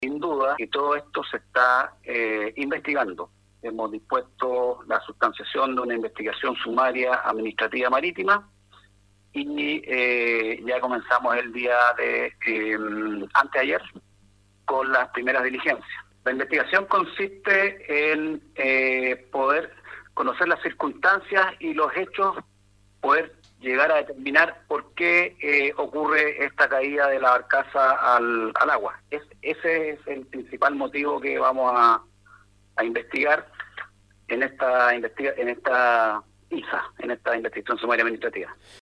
La Autoridad Marítima de Talcahuano dispuso una Investigación Sumaria Administrativa Marítima en averiguación de las causas, circunstancias y responsables si los hubiere, informó el gobernador marítimo.
29-GOBERNADOR-MARITIMO-TALCAHUANO-2.mp3